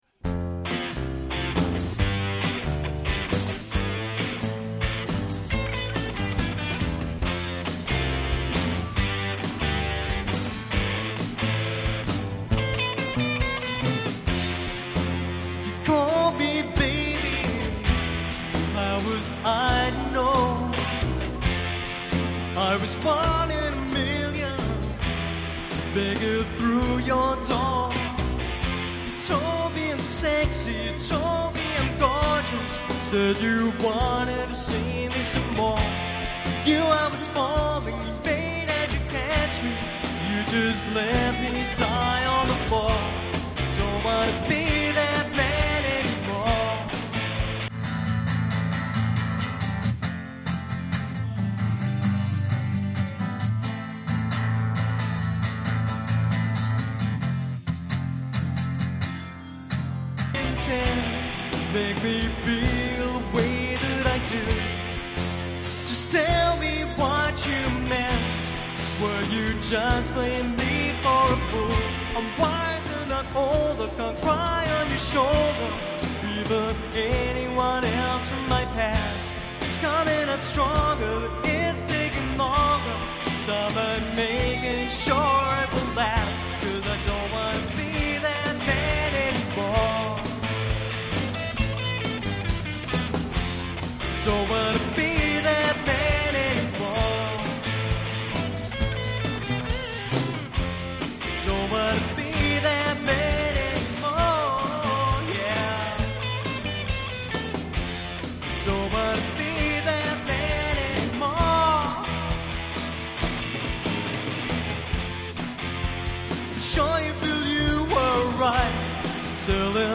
Live performance from The Box in Crewe